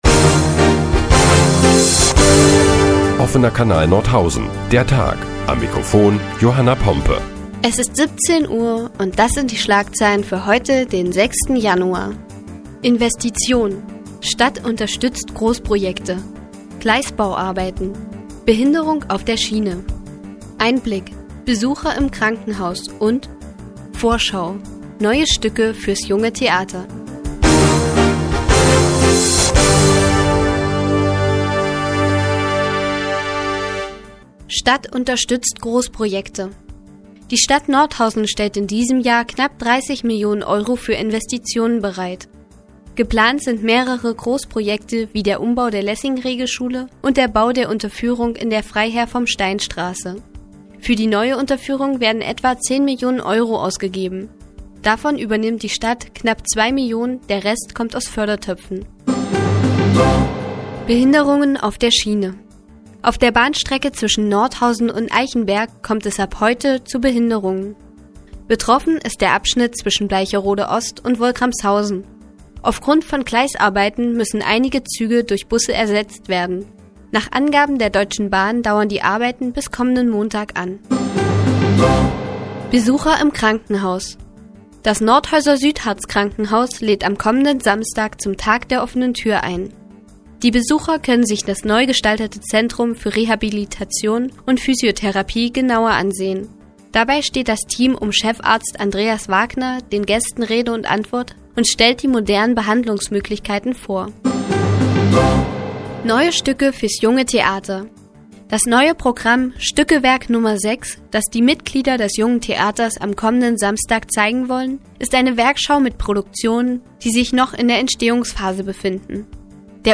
Die tägliche Nachrichtensendung des OKN ist nun auch in der nnz zu hören. Heute geht es unter anderem um die neuen Stücke des Jungen Theaters und Behinderungen auf Bahnstrecken.